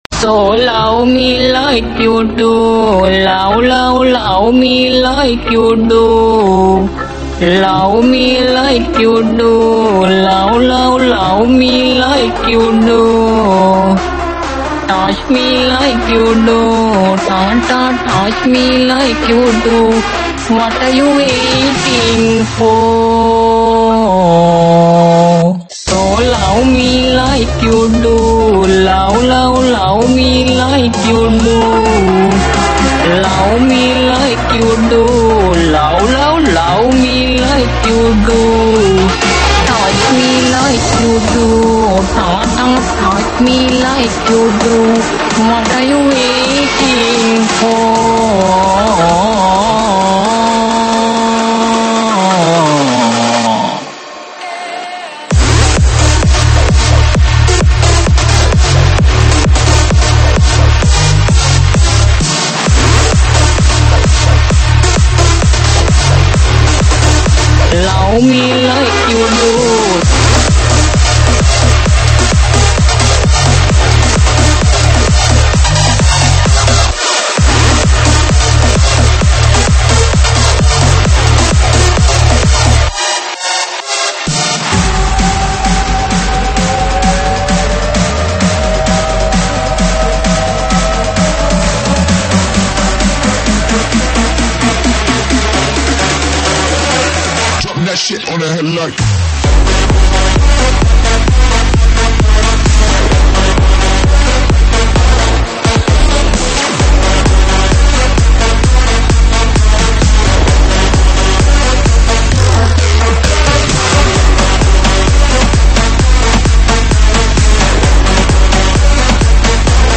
舞曲类别：独家发布